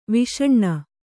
♪ viṣaṇṇa